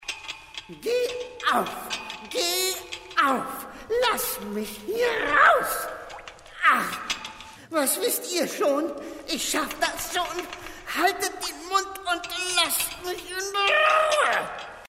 Mittel minus (25-45)
Bayrisch
Audio Drama (Hörspiel)